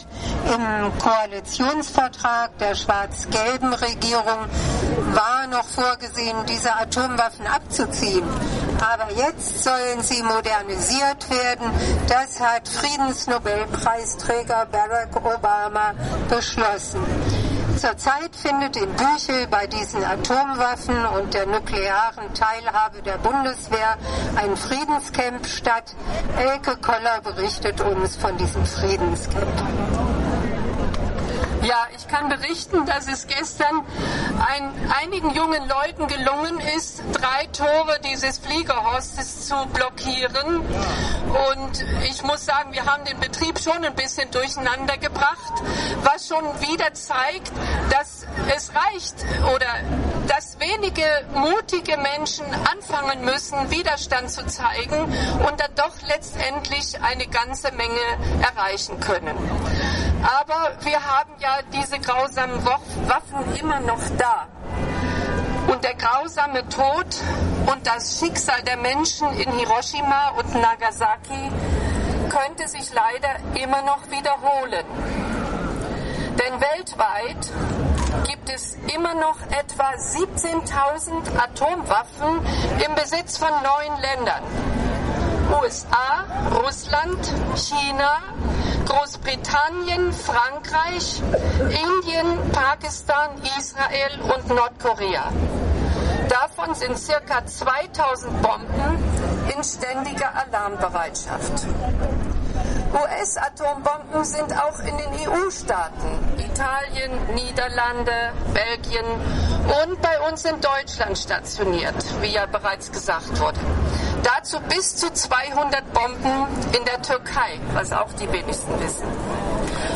Rede
Verstrahltes Leben Kundgebung zum Hiroshima-Nagasaki-Tag 2014, Erinnerung an die atomare Katastrophe 1945 und Mahnung wegen vorhandener Atomwaffen, auch in Deutschland